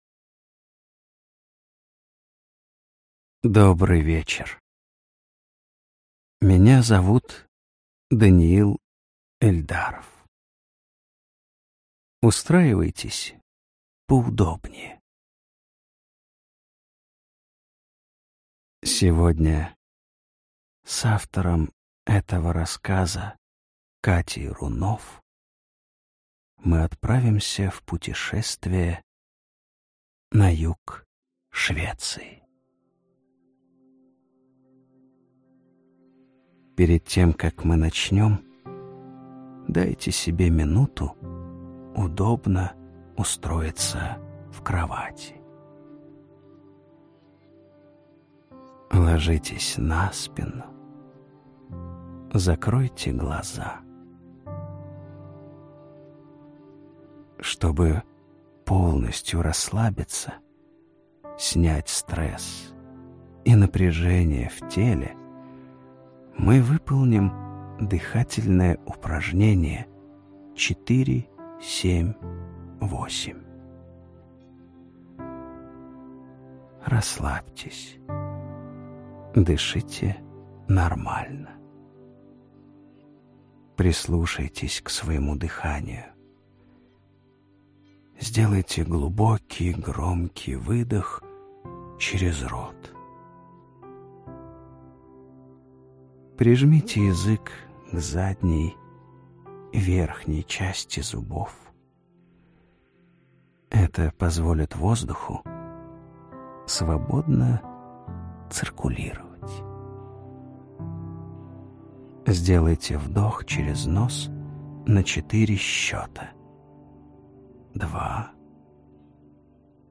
Аудиокнига Остерленский экспресс | Библиотека аудиокниг